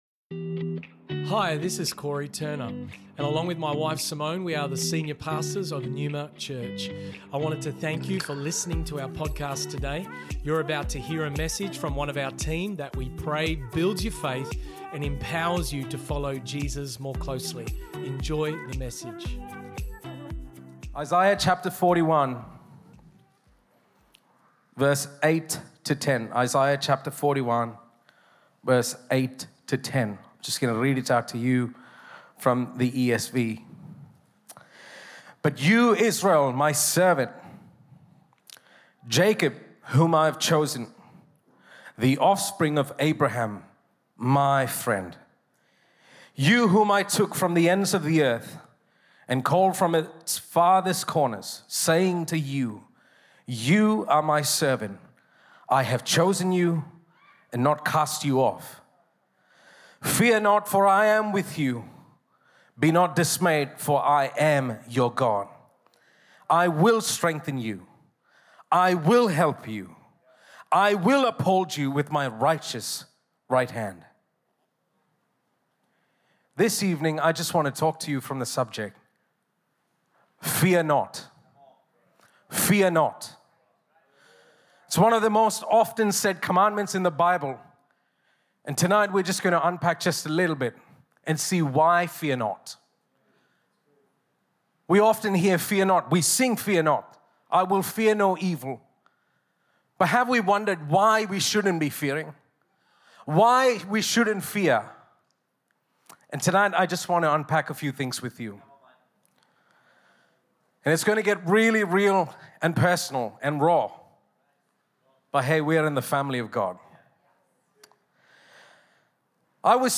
Neuma Church Originally Recorded at the 4PM service on the 26th of March, 2023&nbsp